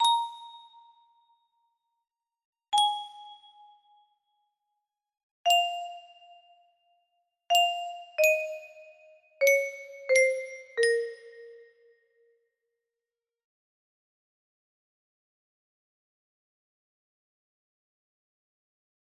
ST3 music box melody